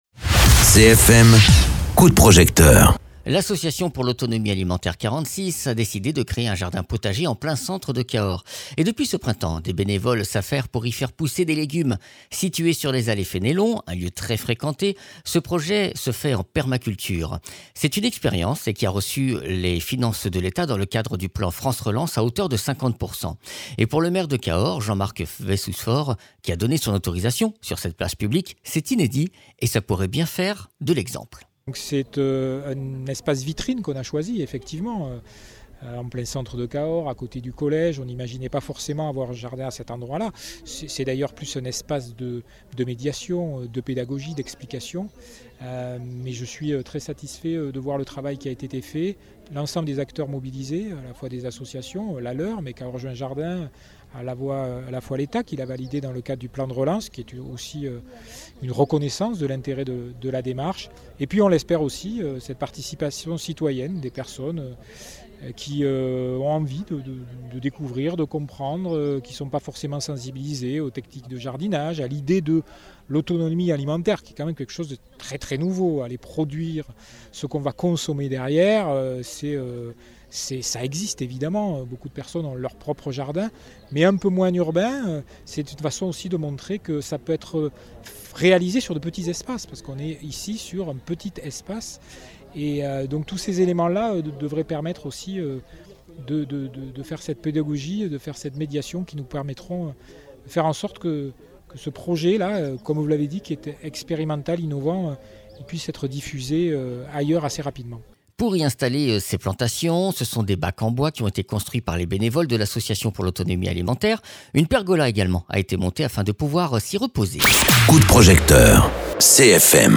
Interviews
Invité(s) : Jean Marc Vayssouze Faure, Maire de Cahors